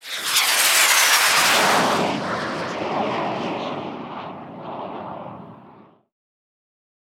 woosh.wav